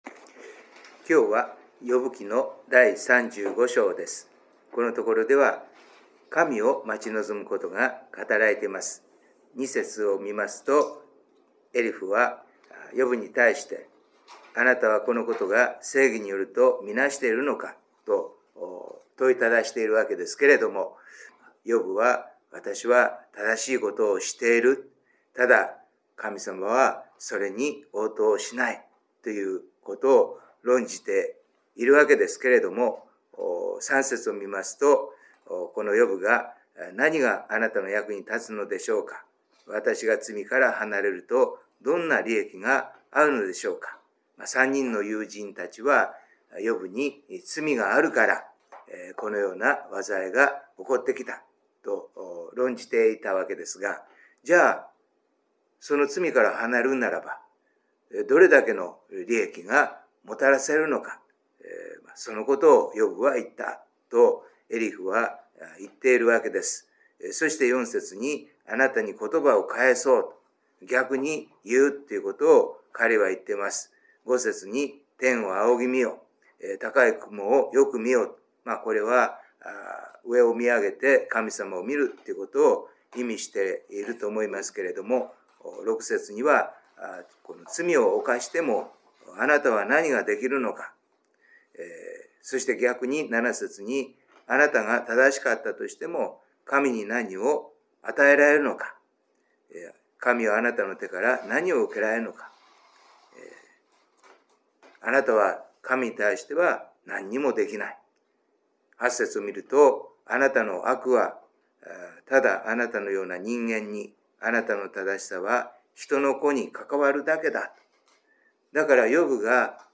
2022/2/2 聖書研究祈祷会